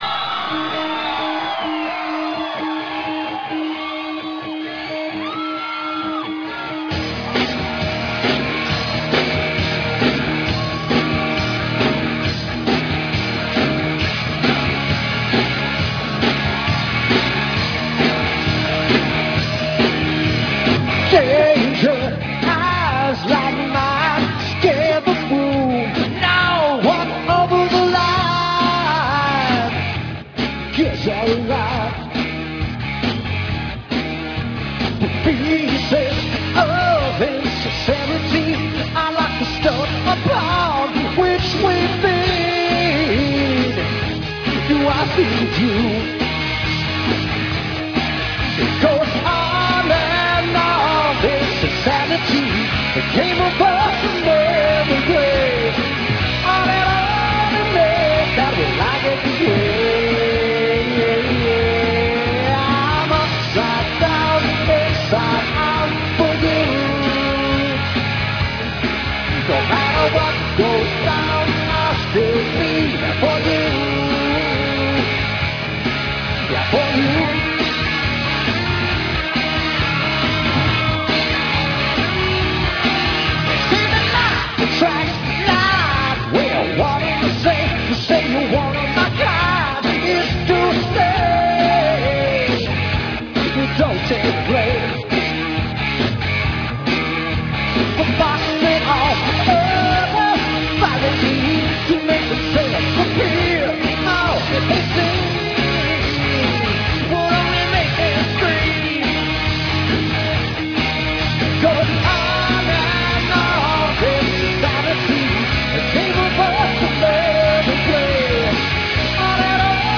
(live)
Liveaufnahme
in New Mexico am 04.07.1997